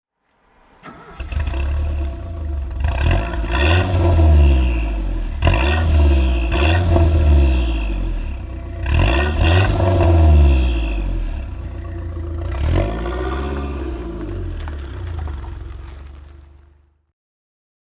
Citroën BX 4 TC (1986) - Starten und Leerlauf
Citroen_BX_4_TC_1986.mp3